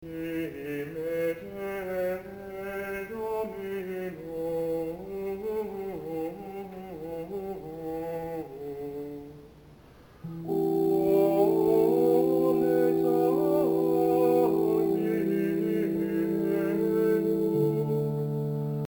Tags: Gregorian Chants Gregorian sounds